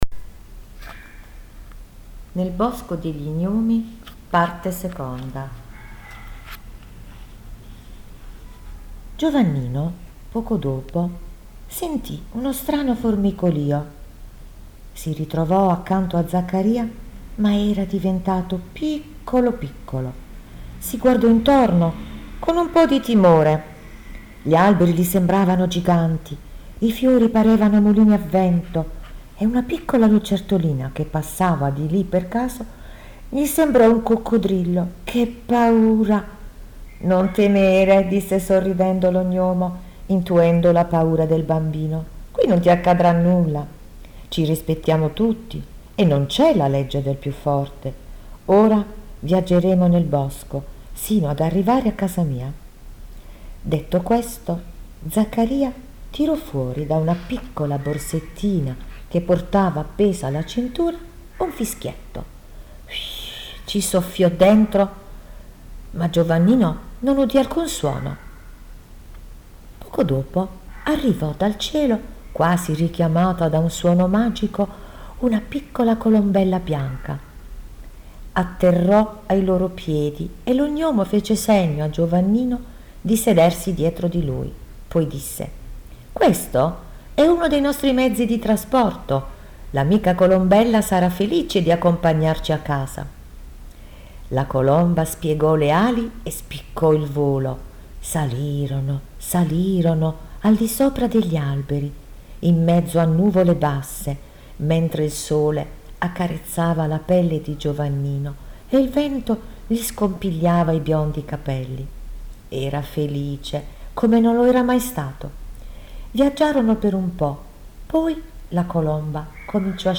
DISPONIBILE ANCHE IN AUDIOLIBRO